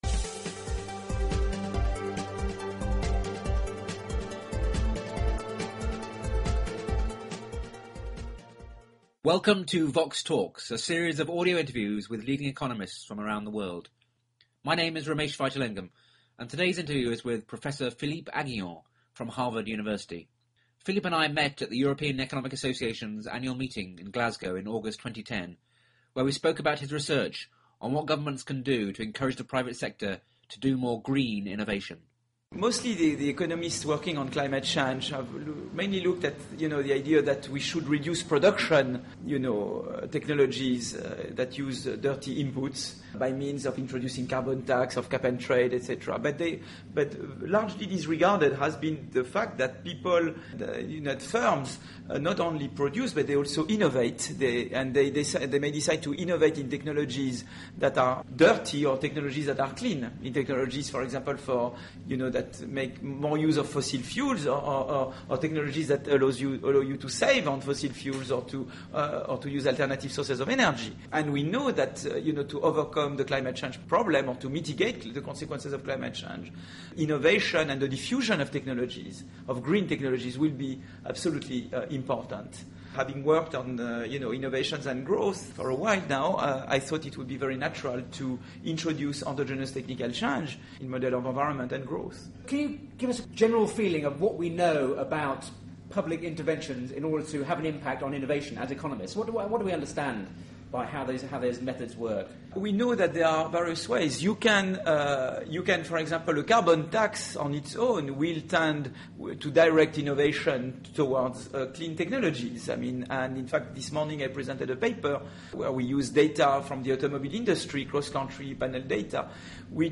He describes the microeconometrics of climate change as virgin territory, where research can make a real difference to the green policy agenda. The interview was recorded at the annual congress of the European Economic Association in Glasgow in August 2010.